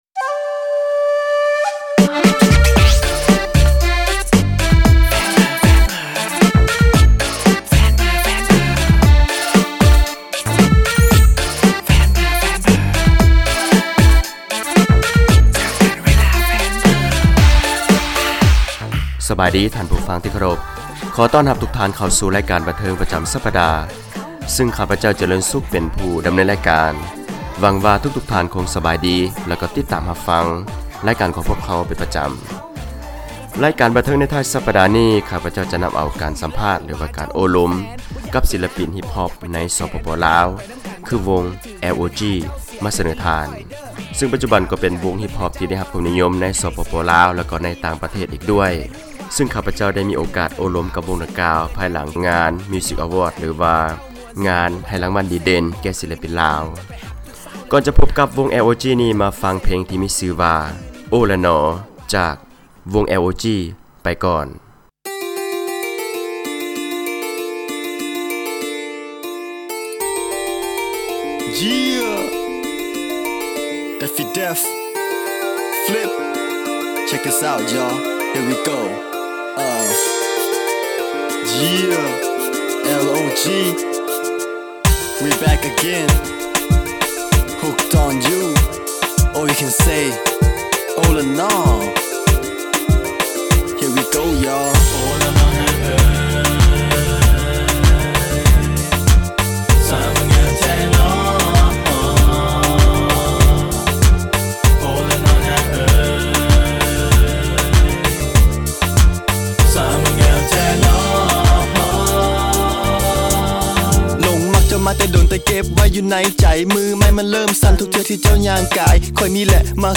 ການສັມພາດ